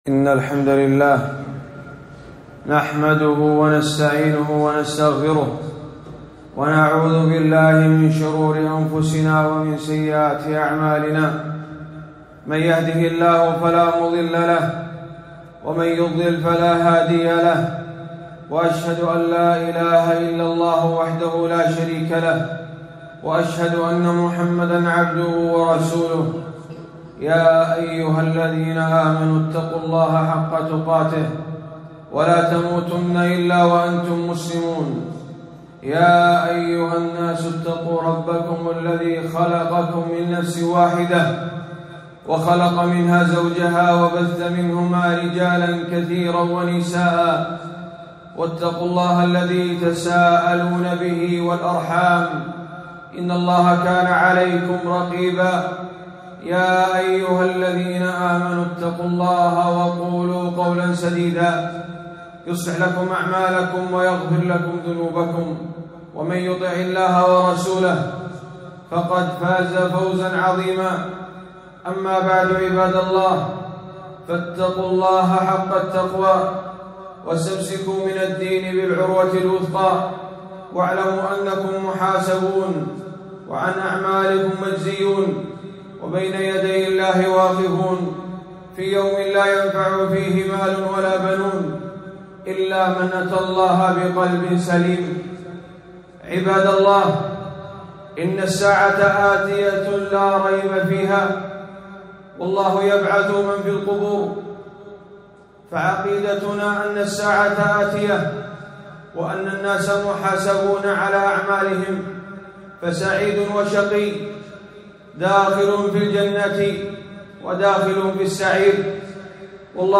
خطبة - التذكرة ببعض أحوال الآخرة